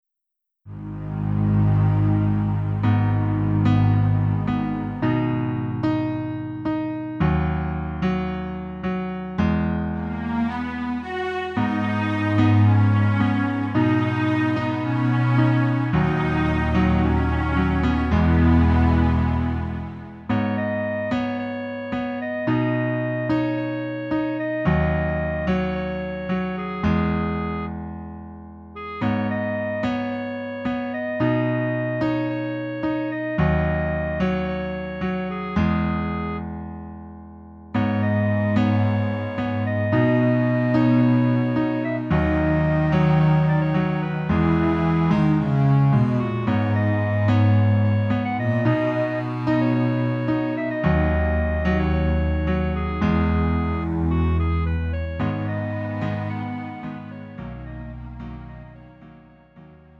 음정 -1키 4:45
장르 구분 Lite MR